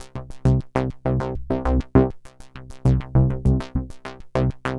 tx_synth_100_fatsaws_C3.wav